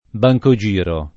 bancogiro [ ba j ko J& ro ] s. m.; pl. ‑ri